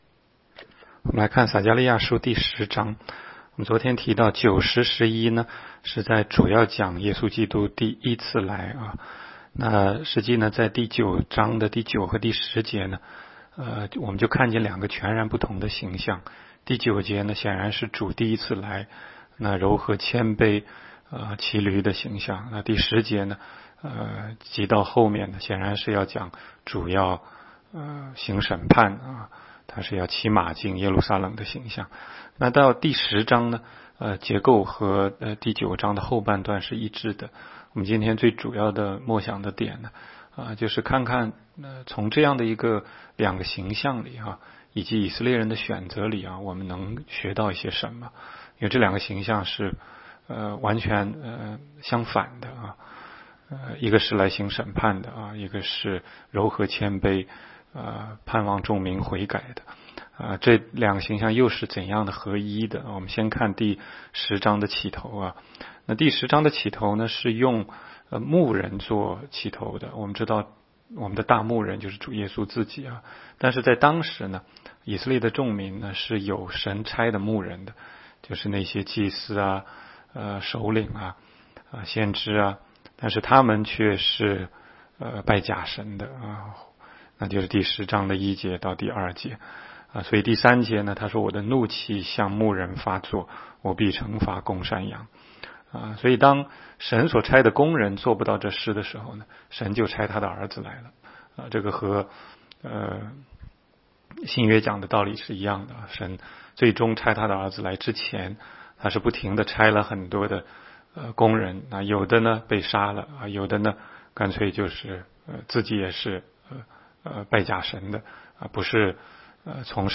16街讲道录音 - 每日读经 -《撒迦利亚书》10章